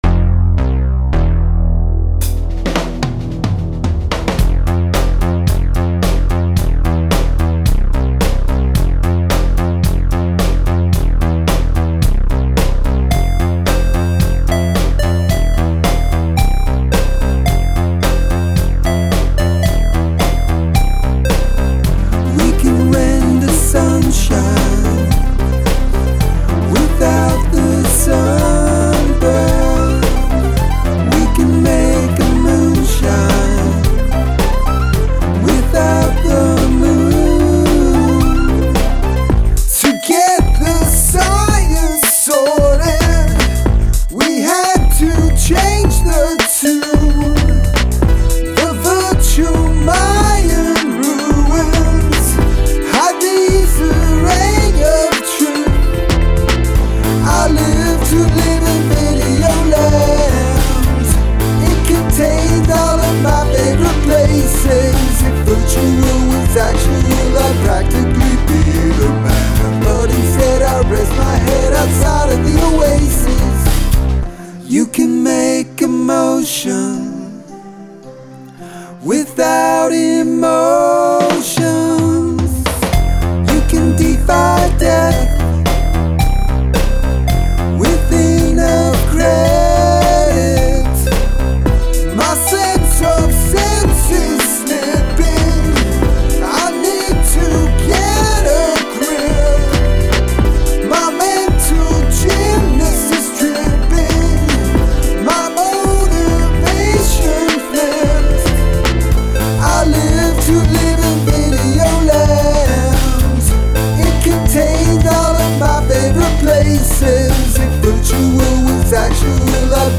Must include a classical music sample